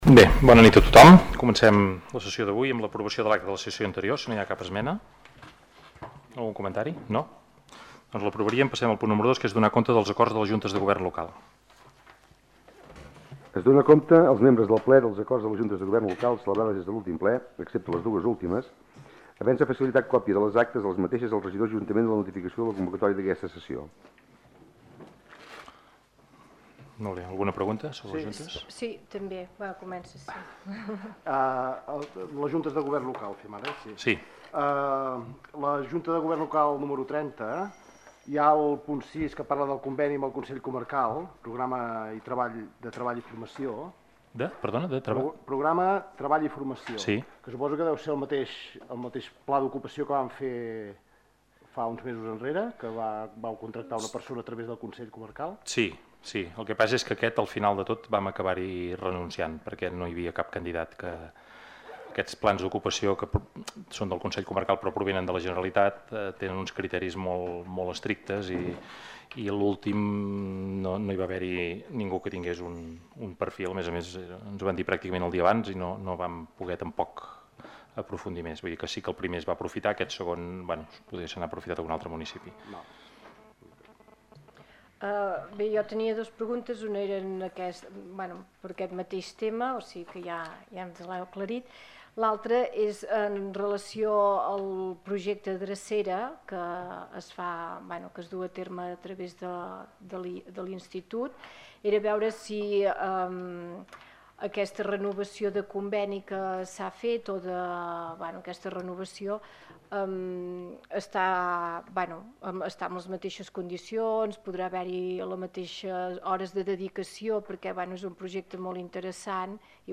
El passat dijous es va celebrar el ple ordinari del mes de setembre a l'Ajuntament de Taradell. Va ser un ple de tràmit i amb poques qüestions a tractar.